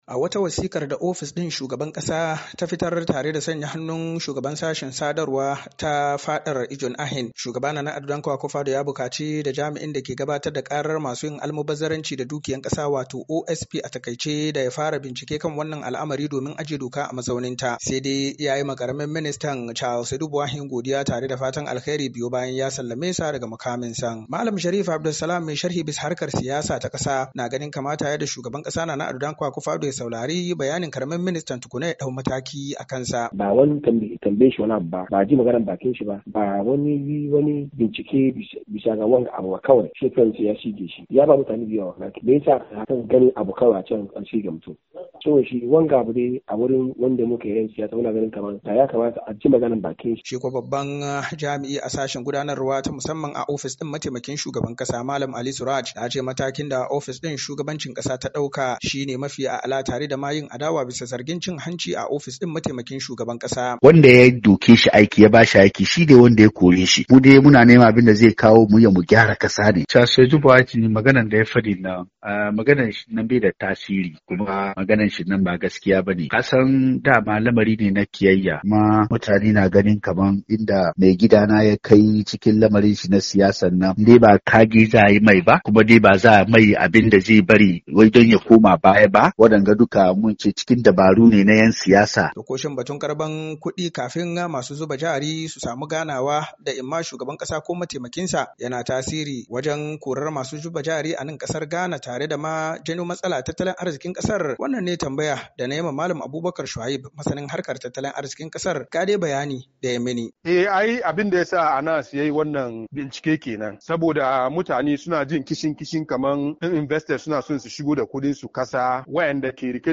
RAHOTON TSIGE ARAMIN MINISTAN KUDIN KASA.mp3